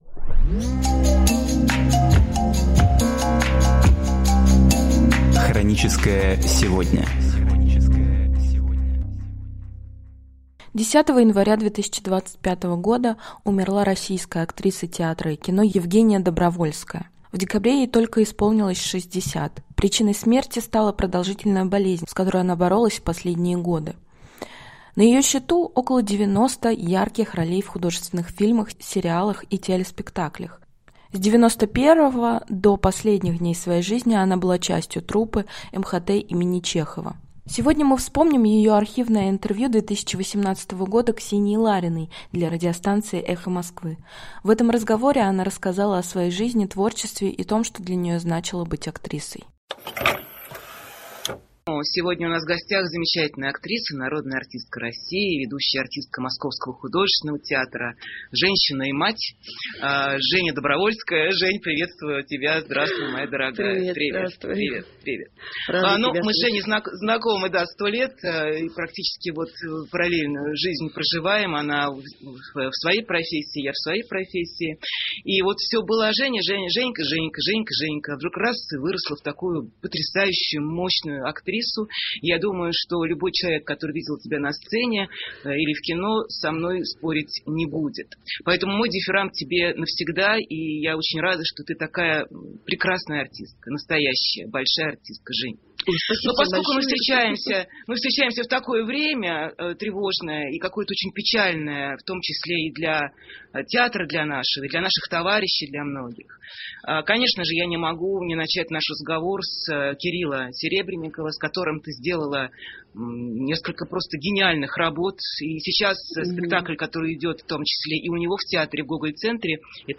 Сегодня мы вспоминаем ее архивное интервью 2018 года Ксении Лариной для радиостанции «Эхо Москвы». В этом разговоре она рассказала о своей жизни, творчестве и том, что для нее значило быть актрисой.